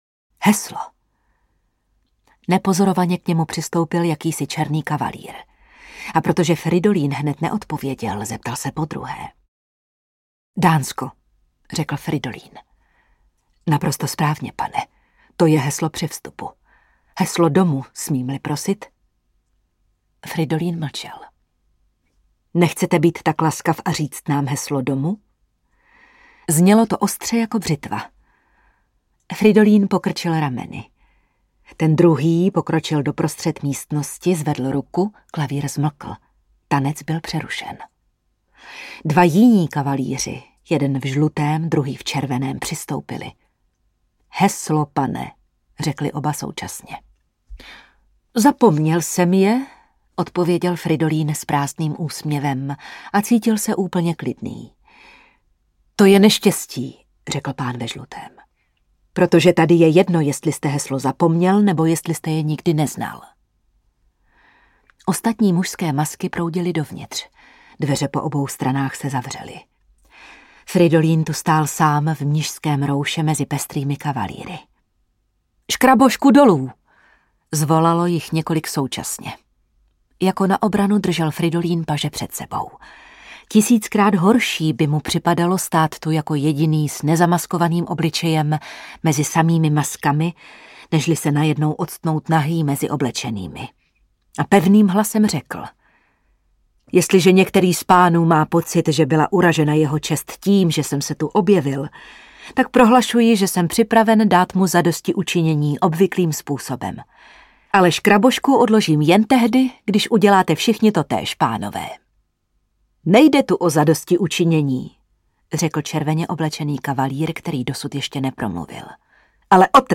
Snová novela audiokniha
Ukázka z knihy
Vyrobilo studio Soundguru.